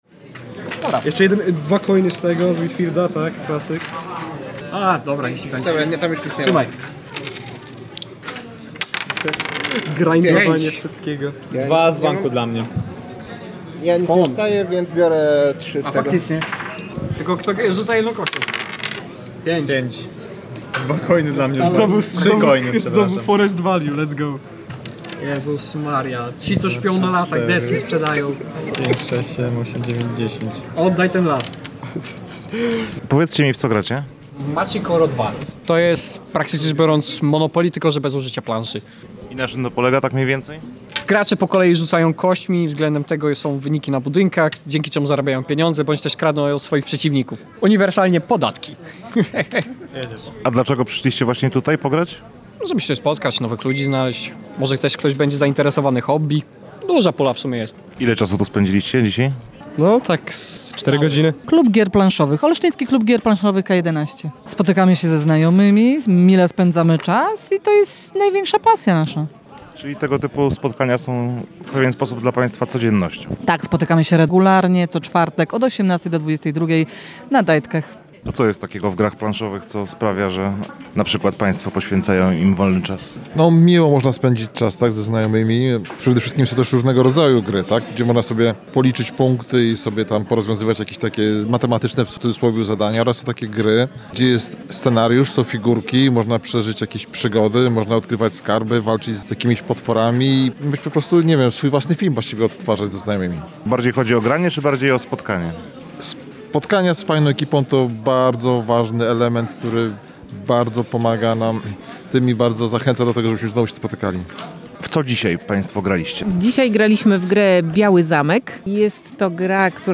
rozmawiała także z uczestnikami Gamegrindera
0906-MA-Gamegrinder-uczestnicy.mp3